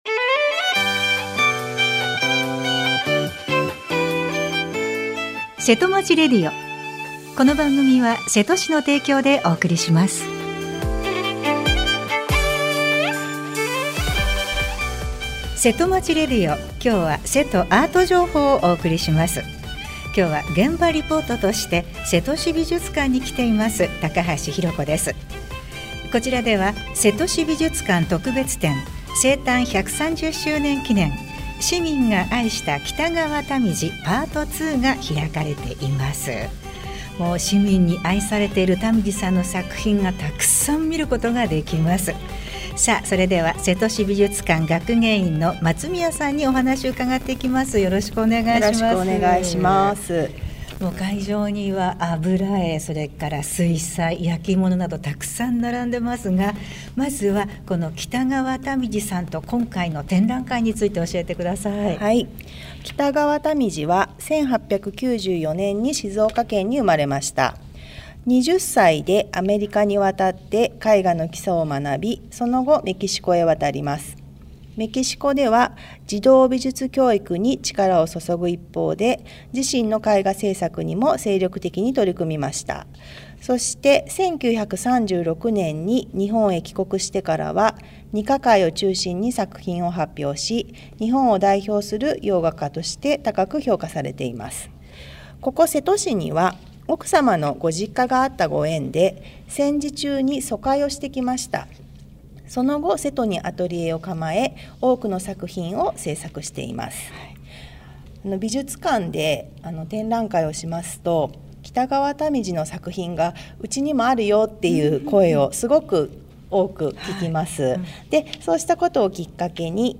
今回のせとまちラジオは、伊藤保德 瀬戸市長からの新年のごあいさつです。